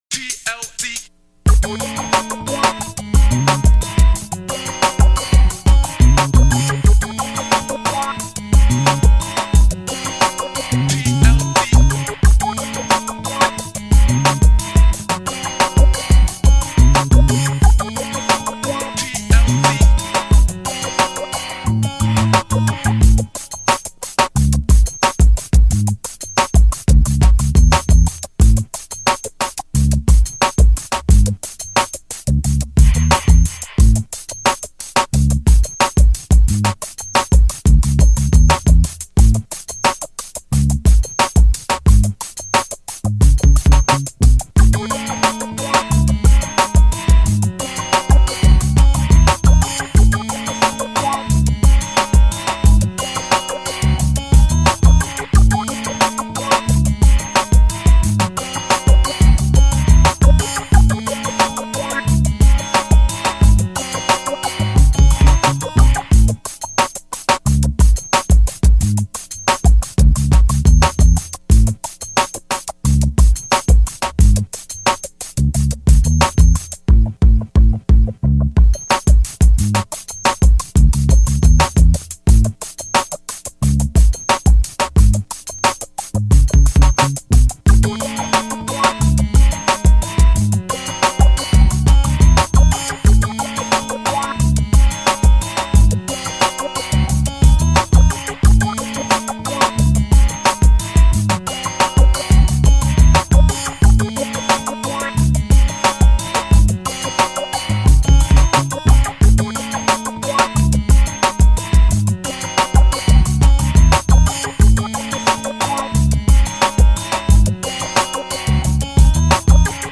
Rhythm and Blues Instrumentals